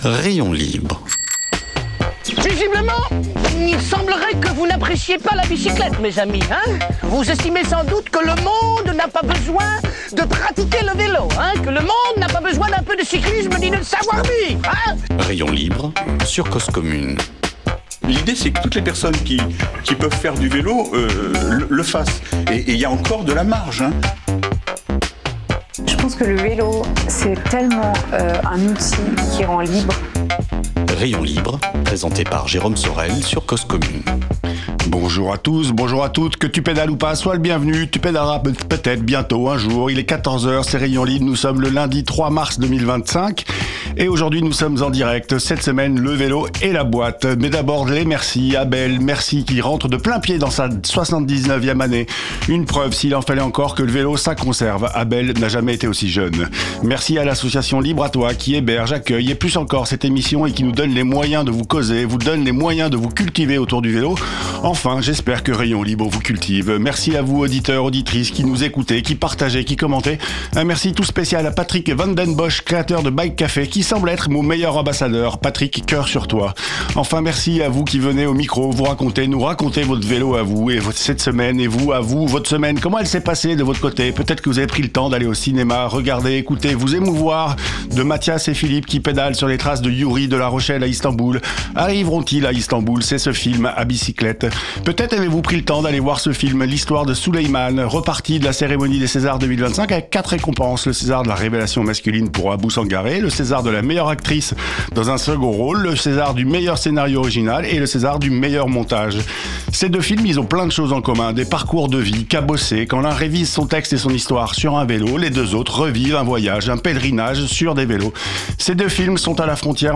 Émission proposée en partenariat avec Weelz! .